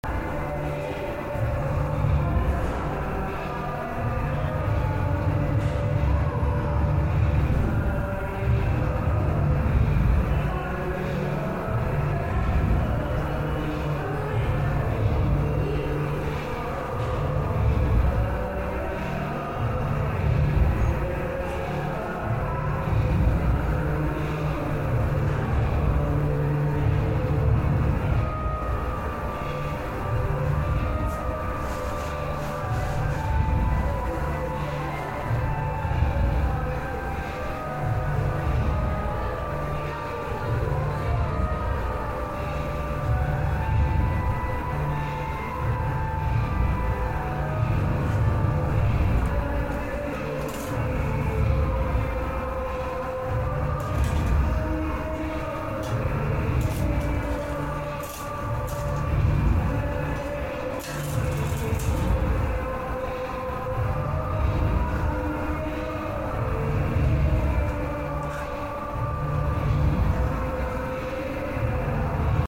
The late-night sounds of Bhakti Park, Mumbai, India - as heard and recorded